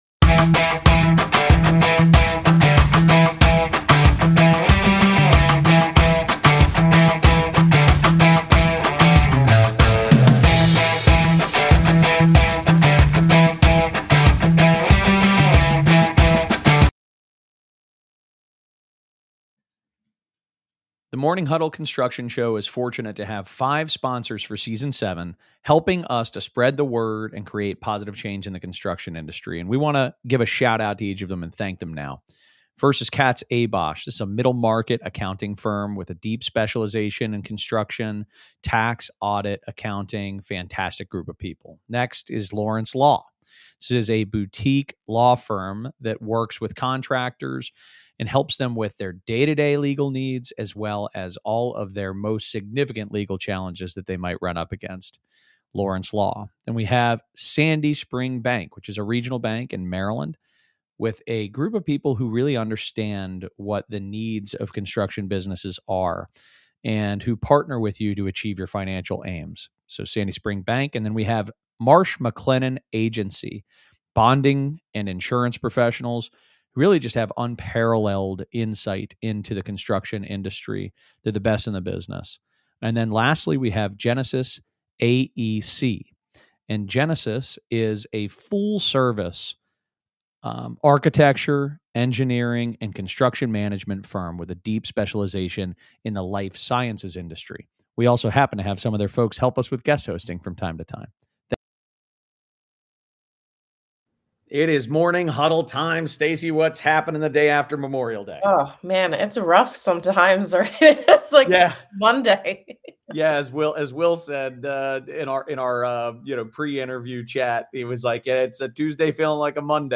A construction industry interview show. The Morning Huddle is a platform for people who are creating positive change in the building industry.